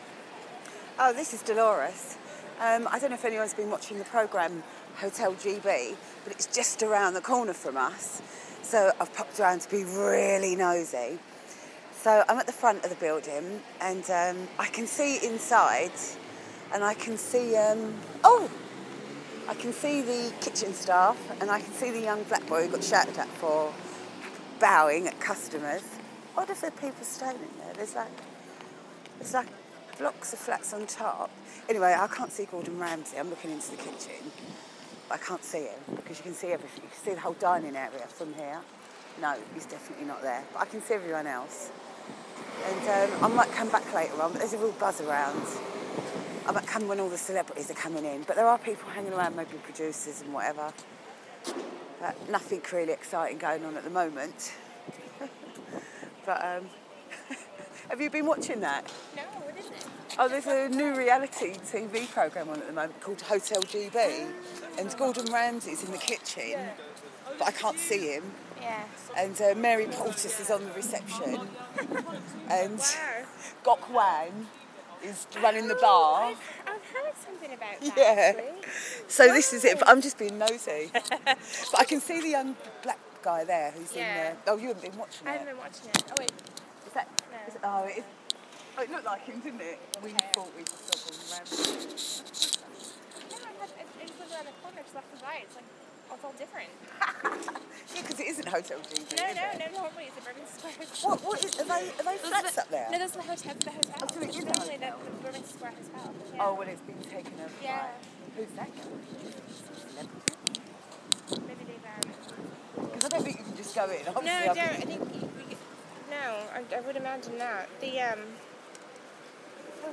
I'm outside Hotel GB but I can't see Gordon Ramsey. It looks busy.